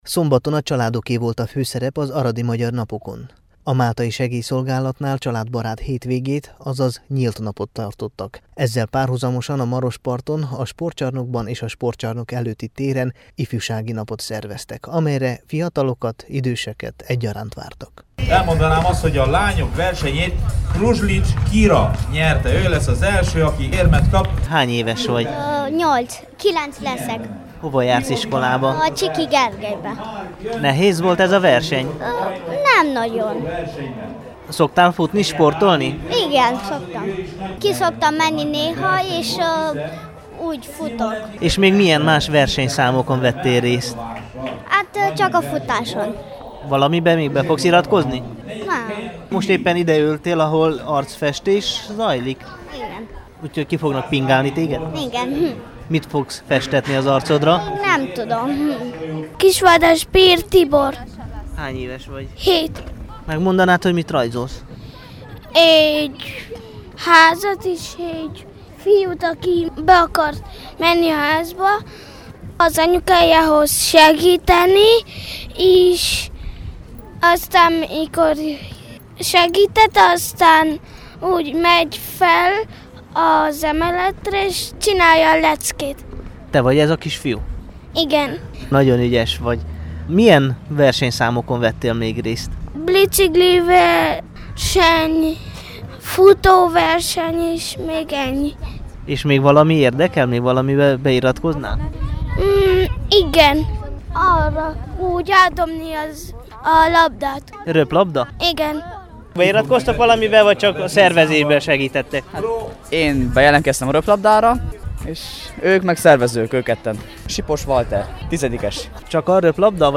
a Temesvári Rádió ifjúsági műsora számára